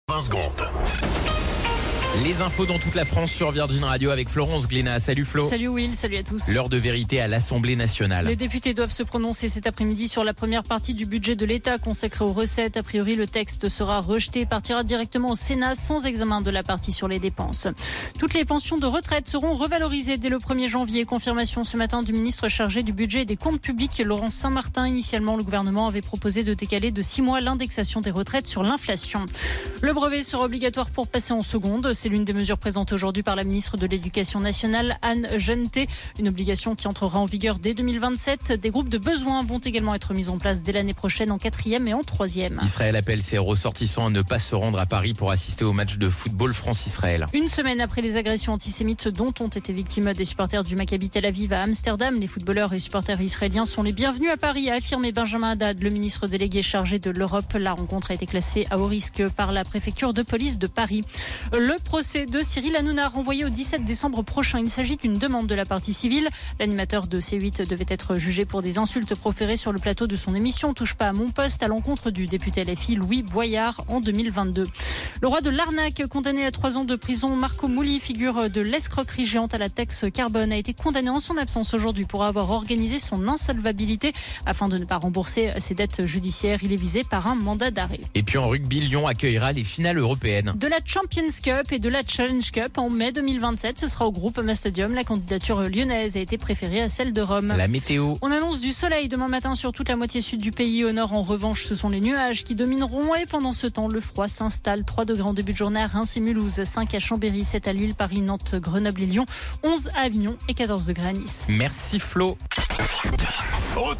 Flash Info National 12 Novembre 2024 Du 12/11/2024 à 17h10 .